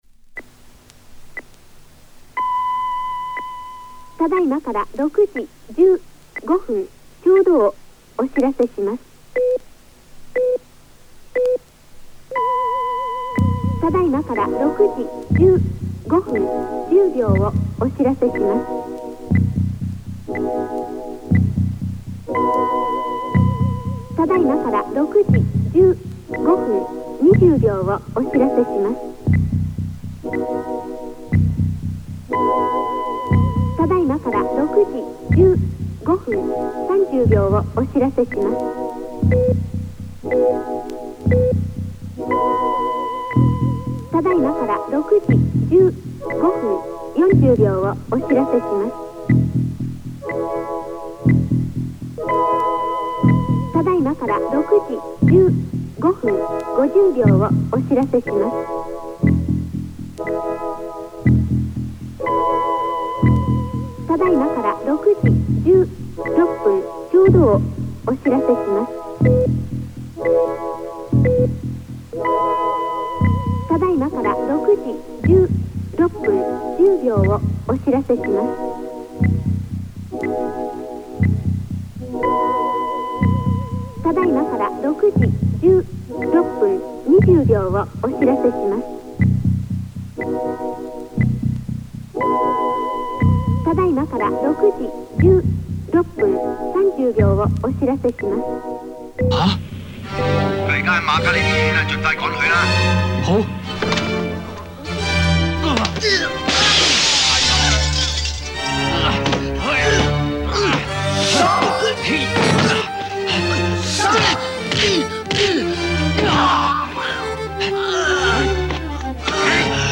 new mix
from lockdown in Brussels
Ambient Rock Synth